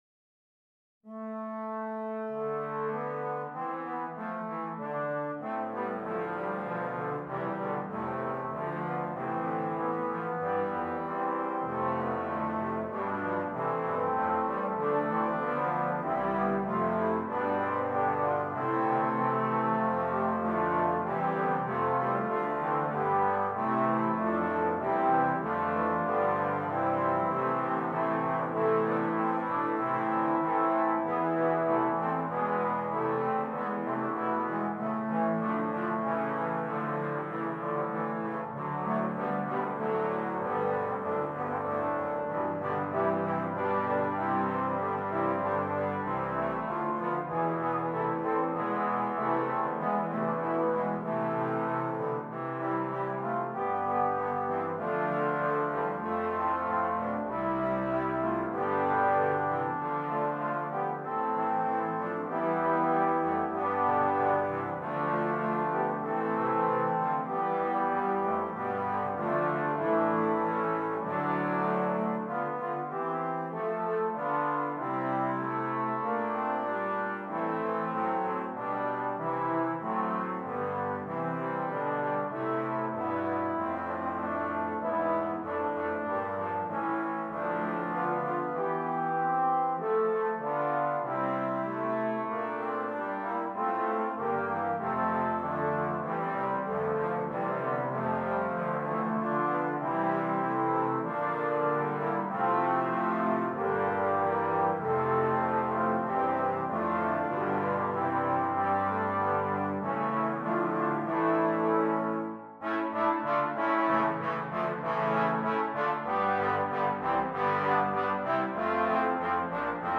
8 Trombones